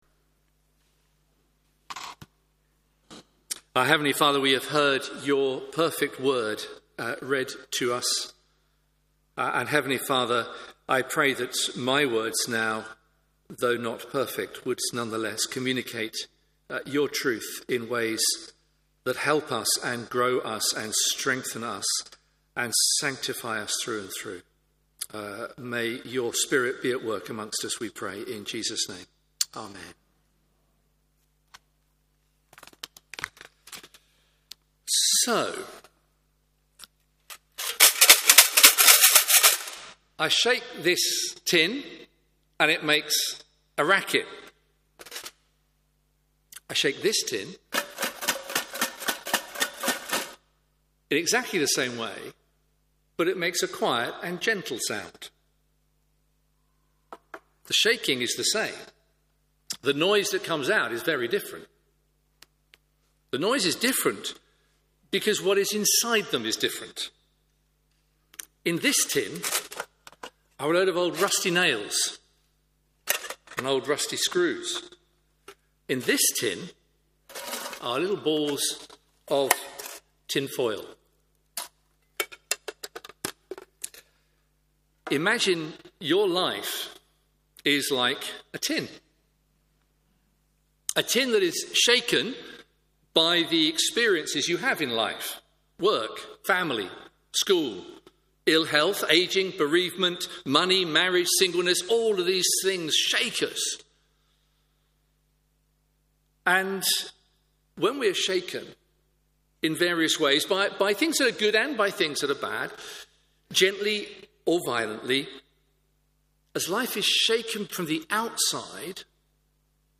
Media for Morning Service on Sun 10th Aug 2025 10:30 Speaker: Passage: Luke 11:1-13, Psalm 141 Series: Psalms Theme: Sermon In the search box please enter the sermon you are looking for.